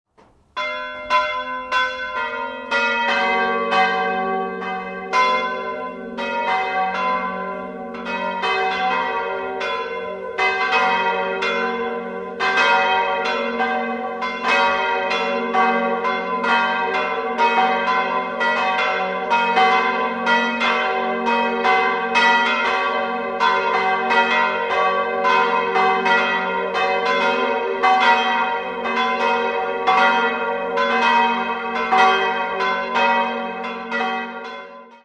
Beschreibung der Glocken
Jahrhunderts wurde das Gotteshaus barockisiert, der Turm existiert in seiner heutigen Form vermutlich seit dem Jahr 1811. 3-stimmiges Geläute: g'-b'-c'' Eine genaue Glockenbeschreibung folgt unten.